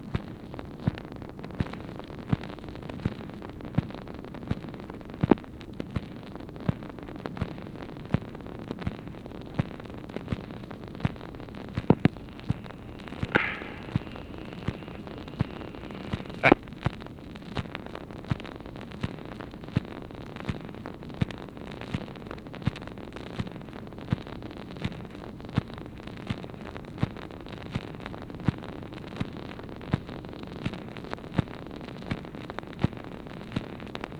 OFFICE NOISE, July 19, 1964
Secret White House Tapes | Lyndon B. Johnson Presidency